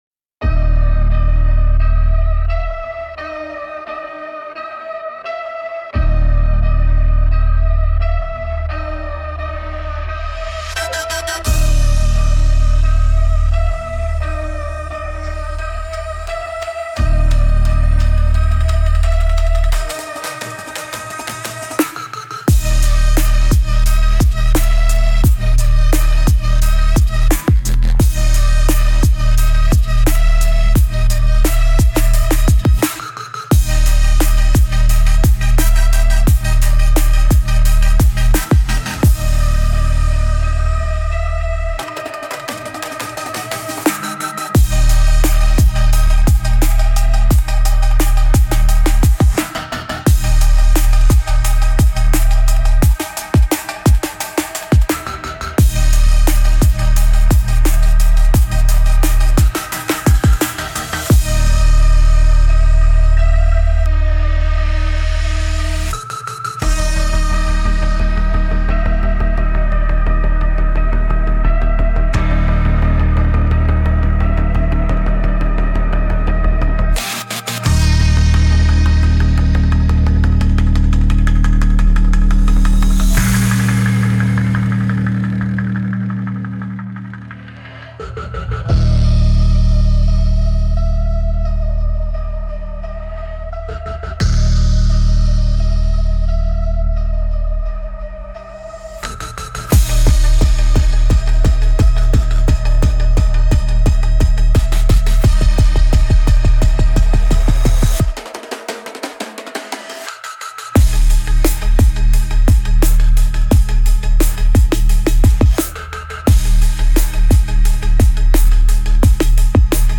Instrumental - Real Liberty Media Dot XYZ -- 4 mins.mp3